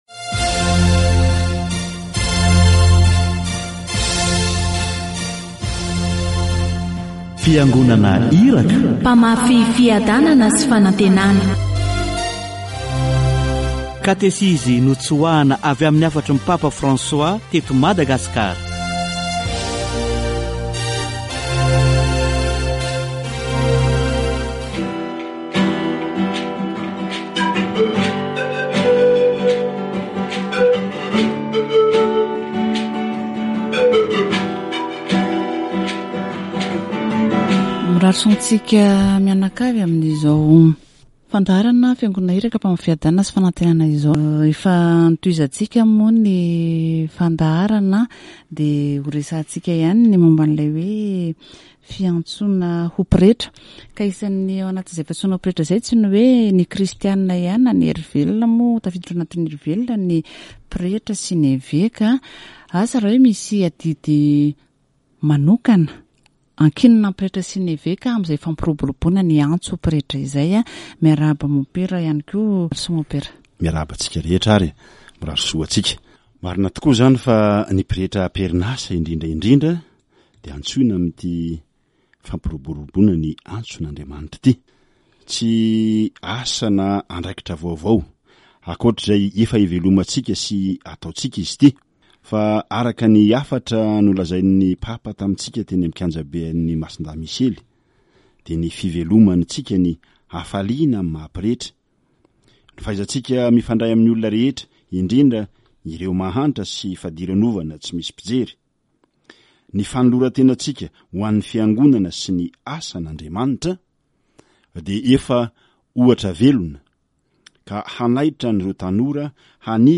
The bishop calls the people to help the priests who are already responding to the call, while presenting himself as a caring father. Prayer with the Blessed Virgin Mary, Mother of God, helps a lot to face it. Catechesis on the promotion of the priestly vocation